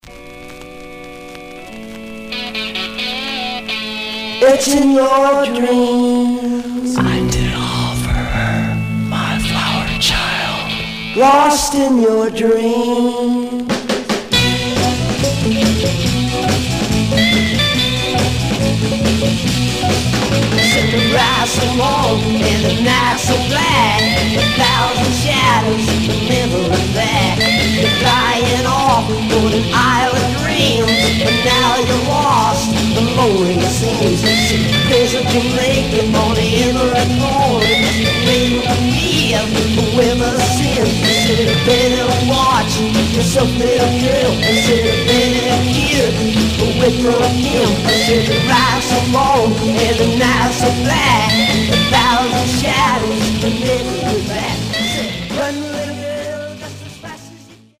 Mono
Garage, 60's Punk